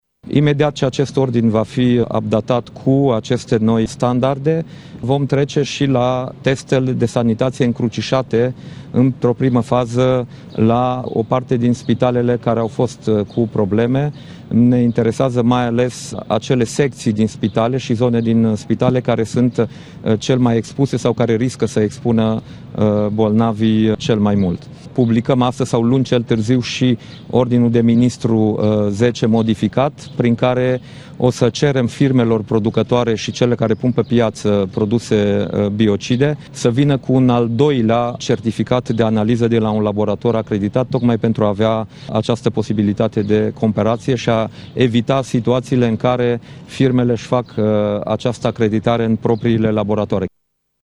Dacian Cioloş a adăugat că a cerut modificarea unui ordin pentru standardele de sanitaţie, în condiţiile în care analizele se fac, dar nu există indicatori de referinţă: